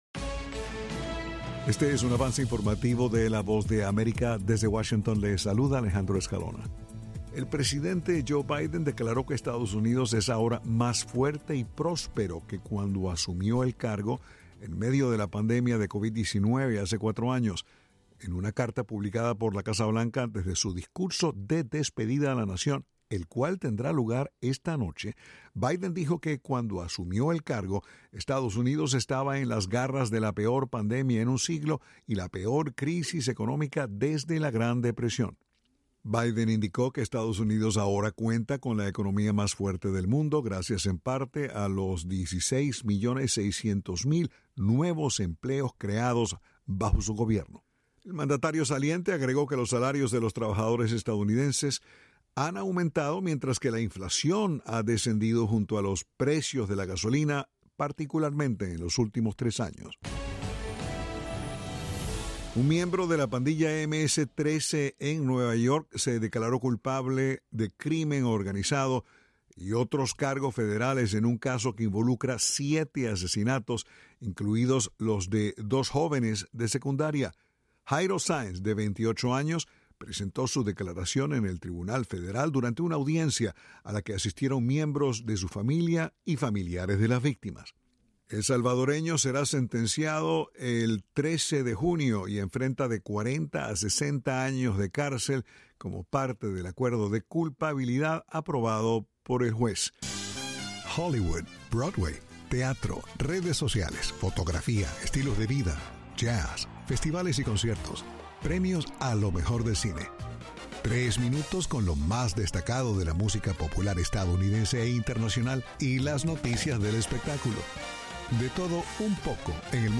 El siguiente es un avance informativo de la Voz de América.
"Avance Informativo" es un segmento de noticias de la Voz de América para nuestras afiliadas en la región de América Latina y el Caribe